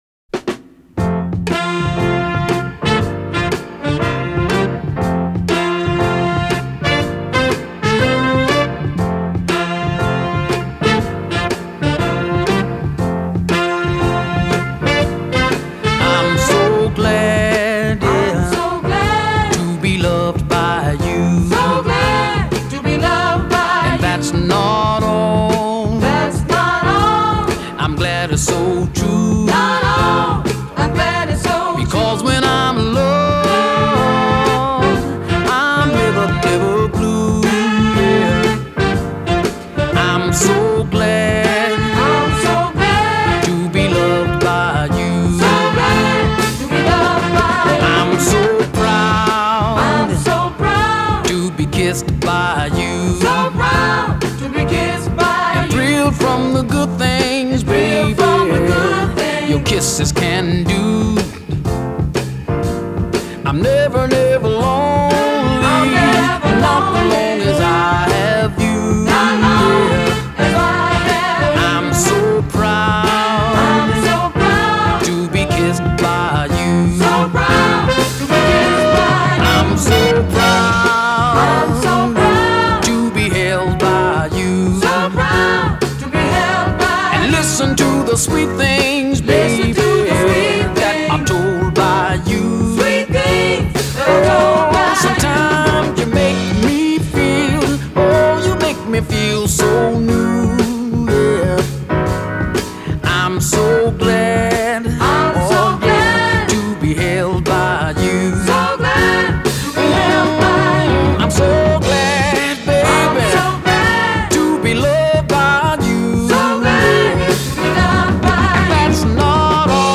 Posted by on December 2, 2014 in Soul/R&B and tagged , , .